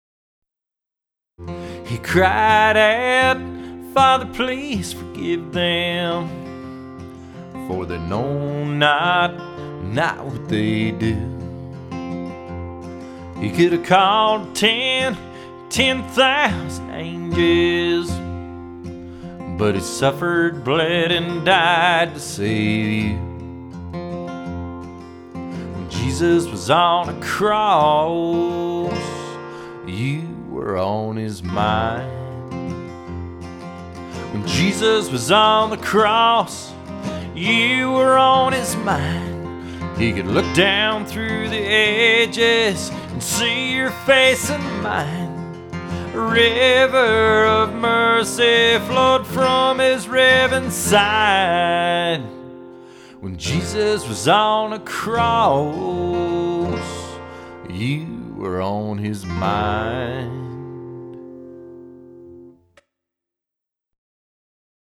They were recorded by the clients who have purchased the KB- 1.
In making these recordings, we tried to keep levels as close as possible.
which consisted of his vocal with acoustic guitar.
It was done as one take.